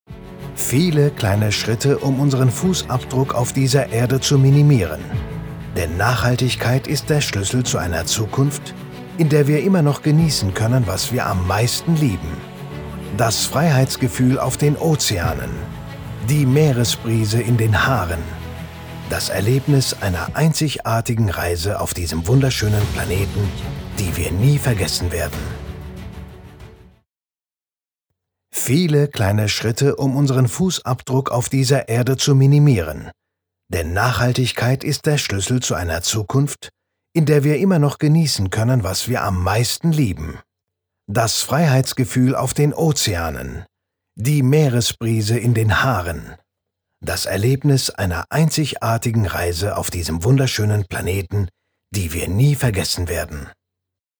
Synchronsprecher in 4 Sprachen: Deutsch, Englisch, Türkisch und Arabisch.
Kein Dialekt
Sprechprobe: Sonstiges (Muttersprache):